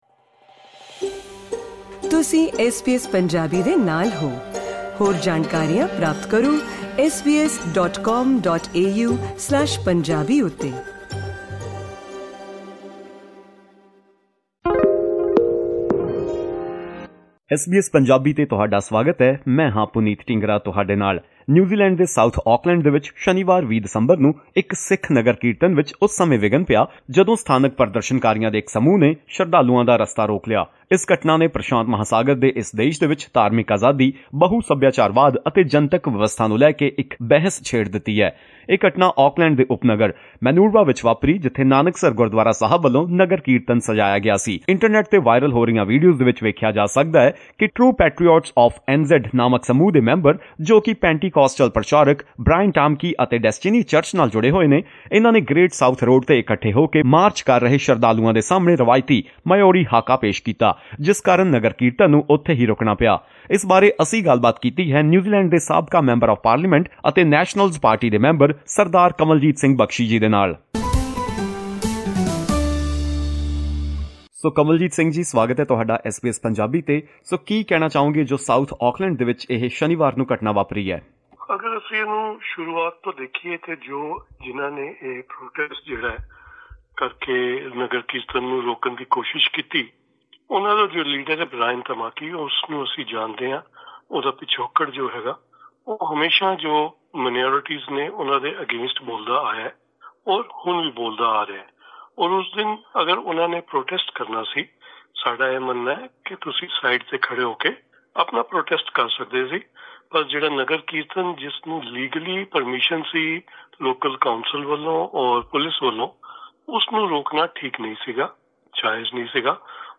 We discussed this incident with Sardar Kanwaljit Singh Bakshi, former Member of Parliament and member of the National Party.